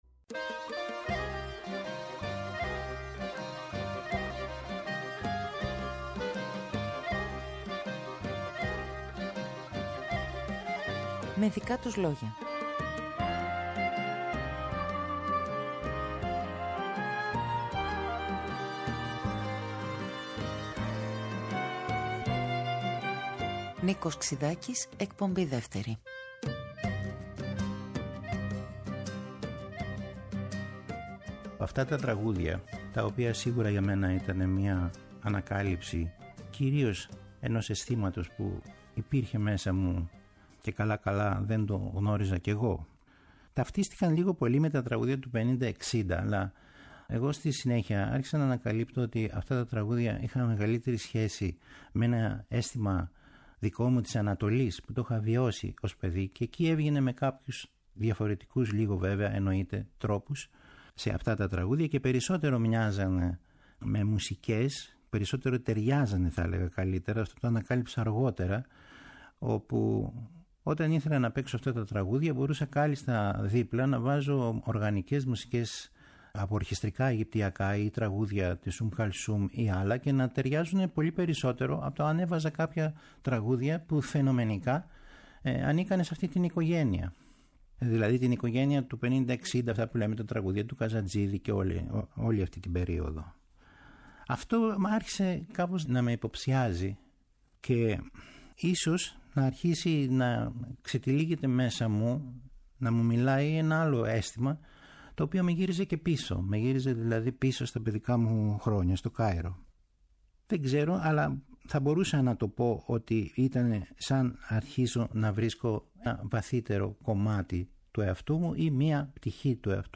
Από την Κυριακή 2 Δεκεμβρίου και για τις Κυριακές του Δεκέμβρη πίσω από το μικρόφωνο του Δεύτερου Προγράμματος της Ελληνικής Ραδιοφωνίας ο Νίκος Ξυδάκης
Τις Κυριακές του Δεκεμβρίου ο Νίκος Ξυδάκης αφηγείται τη δική του ιστορία.